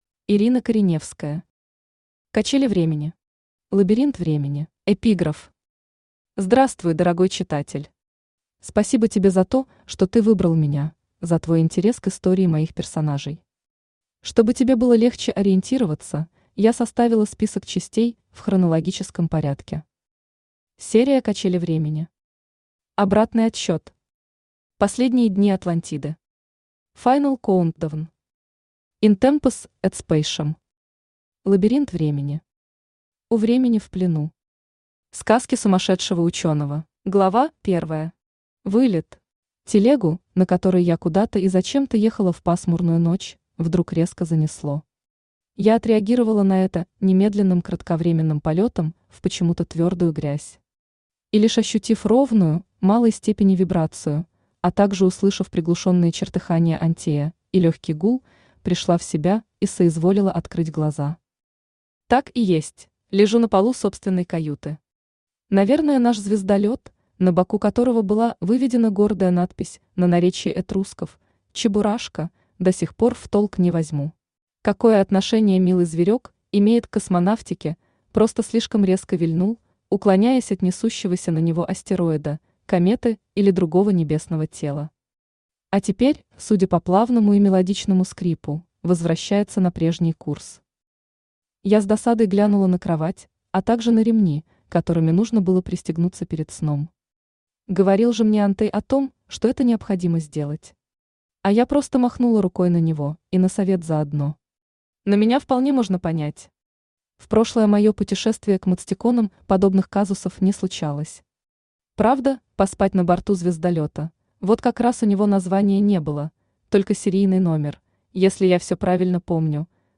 Лабиринт времени Автор Ирина Михайловна Кореневская Читает аудиокнигу Авточтец ЛитРес.